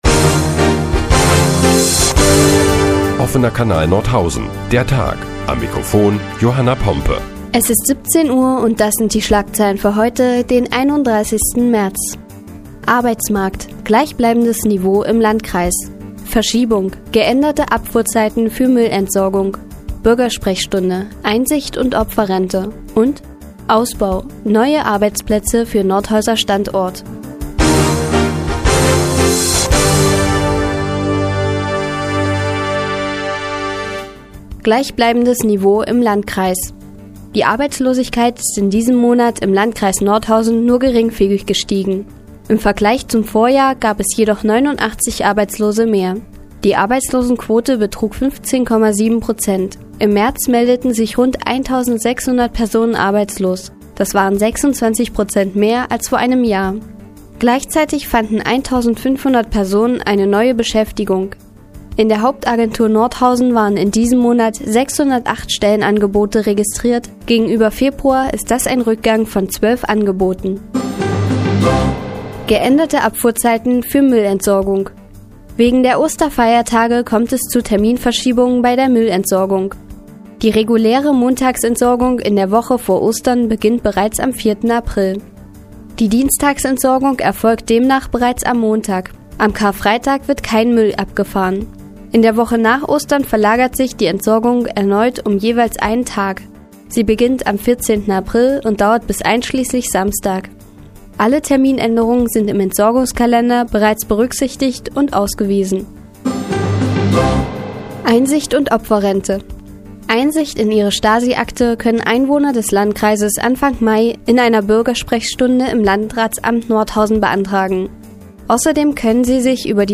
Die tägliche Nachrichtensendung des OKN ist nun auch in der nnz zu hören. Heute geht es unter anderem um den Nordhäuser Arbeitsmarkt und das SED-Unrechtsbereinigungsgesetz.